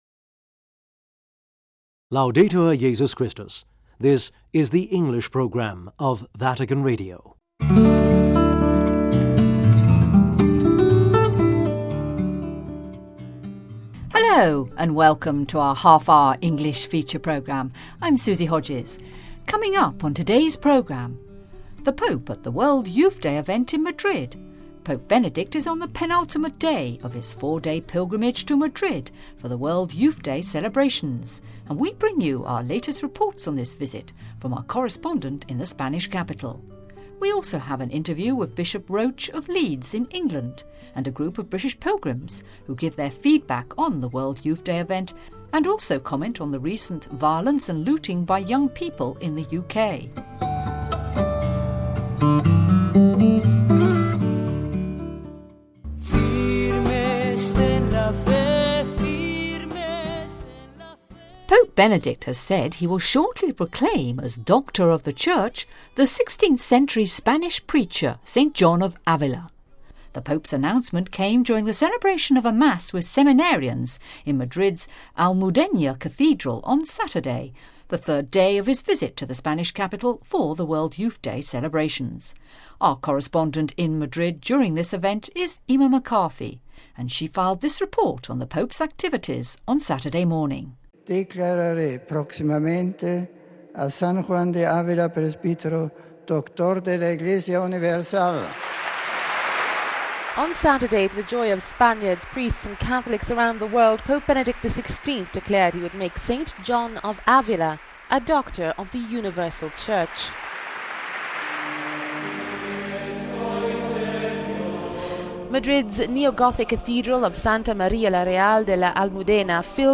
THE POPE AT WYD IN MADRID : Pope Benedict is on the penultimate day of his four-day pilgrimage to Madrid for the World Youth Day celebrations and we bring you our latest reports on this visit from our correspondent in the Spanish capital. We also have an interview with Bishop Roche of Leeds in England and a group of British pilgrims who give their feedback on the WYD event and also comment on the recent violence and looting by young people in the UK.....